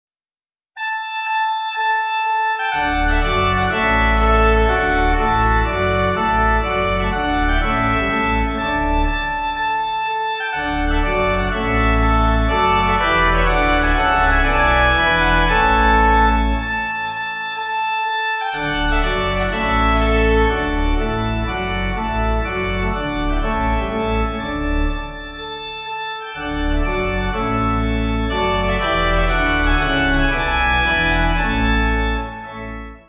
Organ
Easy Listening   1/D